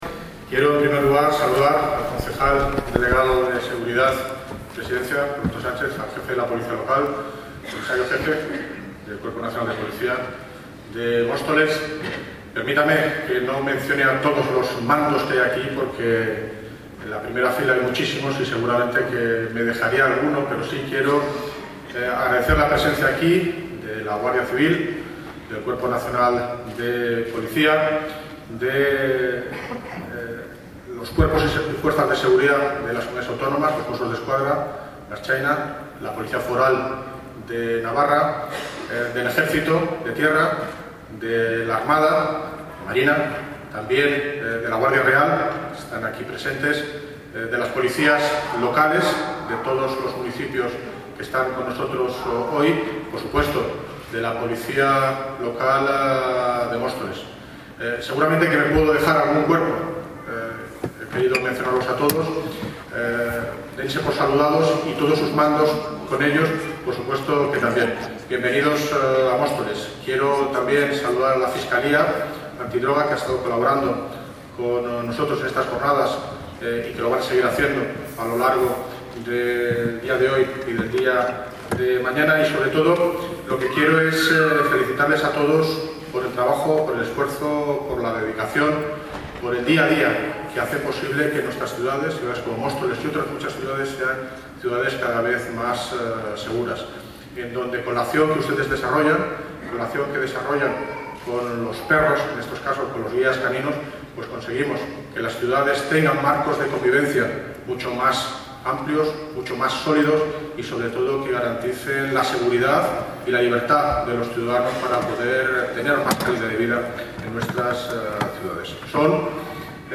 El alcalde preside un emotivo acto en homenaje a policías de las unidades caninas de distintos cuerpos
Audio - David Lucas (Alcalde de Móstoles) Sobre ENTREGA PREMIOS JORNADAS CANINAS